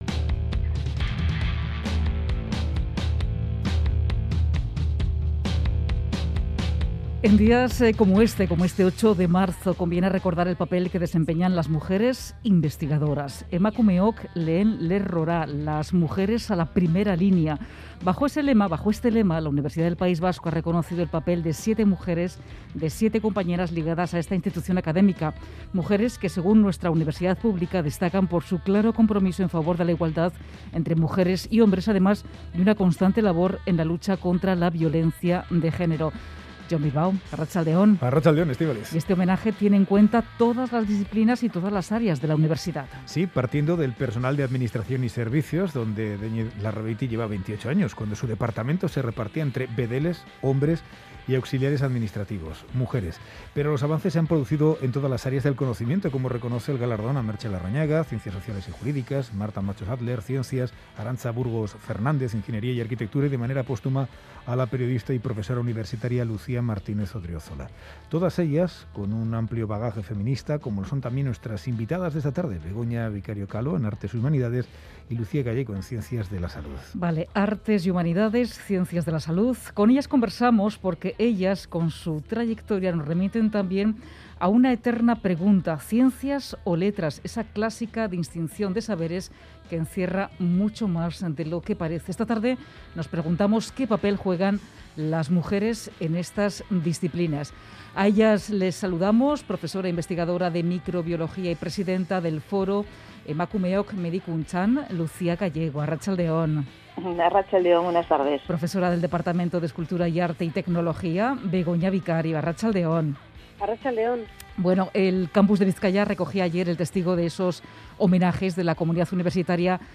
Las profesoras universitarias